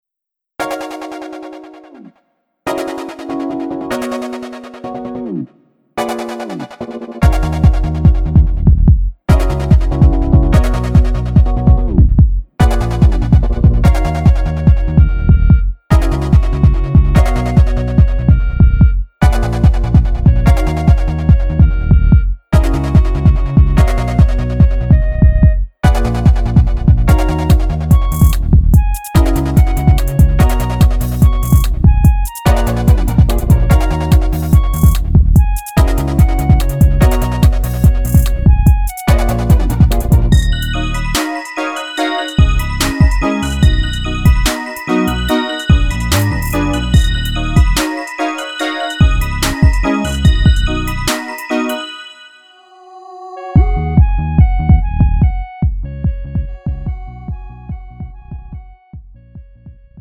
음정 원키
장르 가요
Lite MR